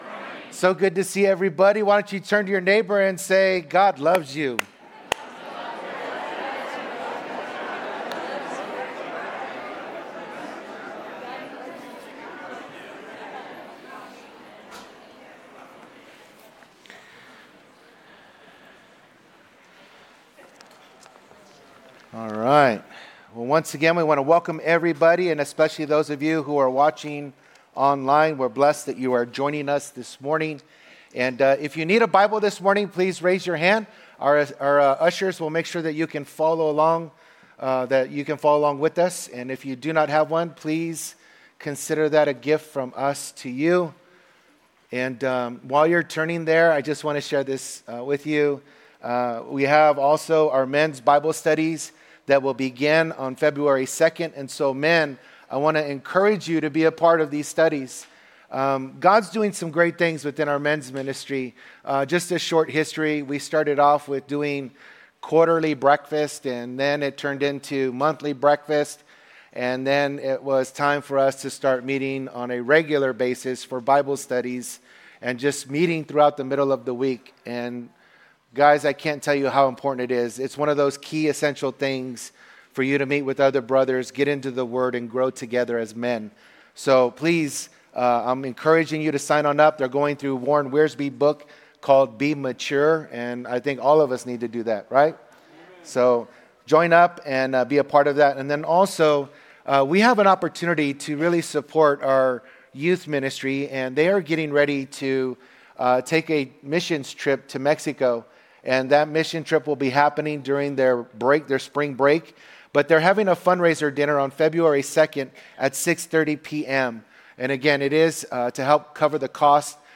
Sunday Mornings